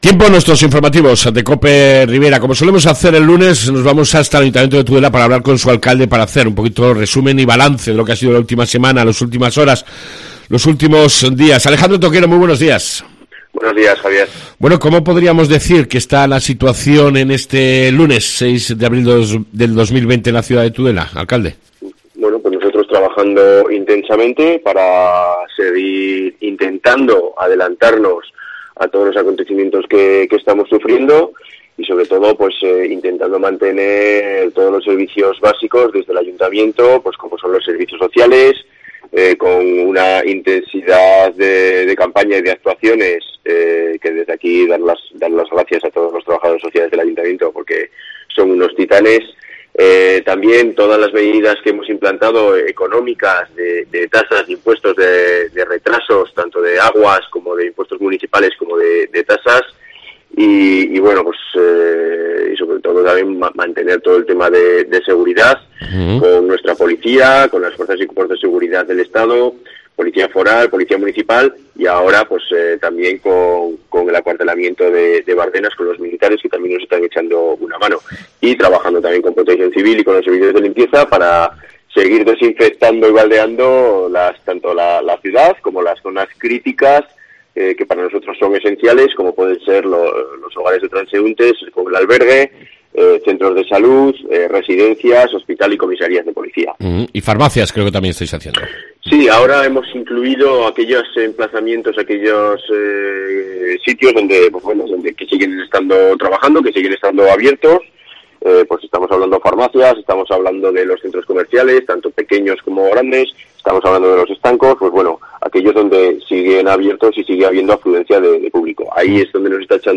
AUDIO: Repasamos la actualidad Tudelana en esta Crisis con su Alcalde Alejandro Toquero